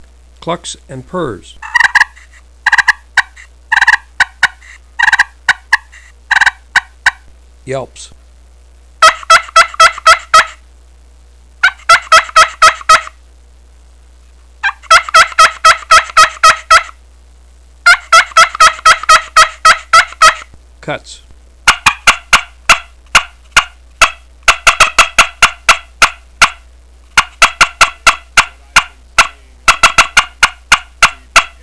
Listen to 31 seconds of clucks & purrs, yelps, and cutts
• A great finisher call to make soft yelps, clucks and purrs to draw a tom into gun range